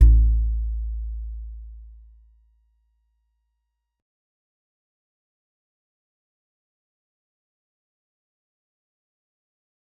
G_Musicbox-A1-mf.wav